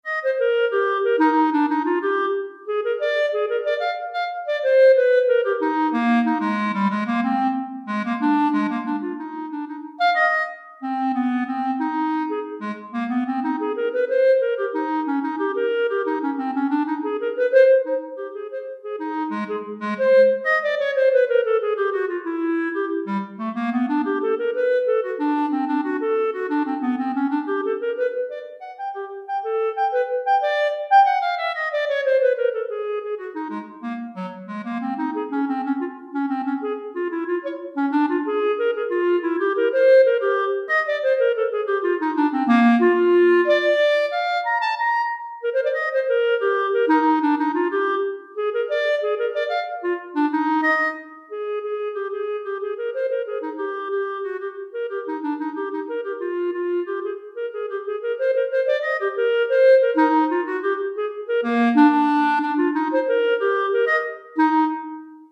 Clarinette Solo en Sib